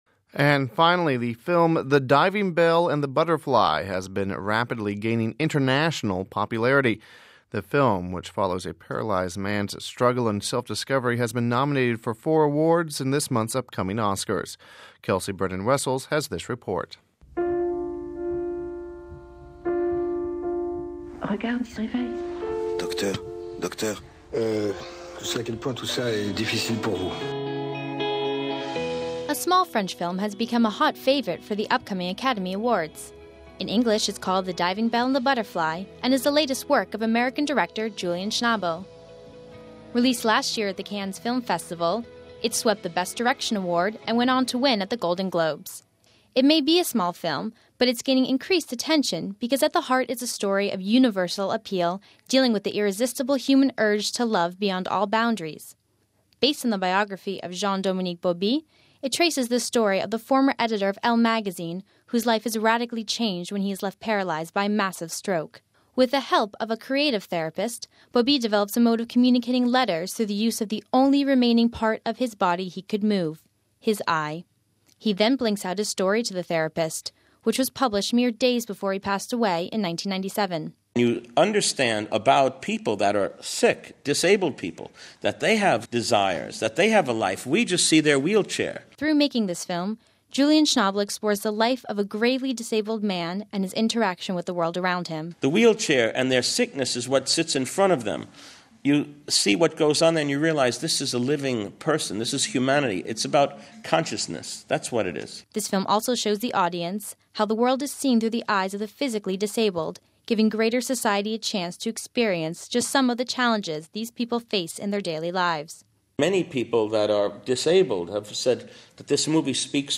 The film which follows a paralyzed man’s struggle and self-discovery has been nominated for four awards in this month’s upcoming Oscars. We have this report...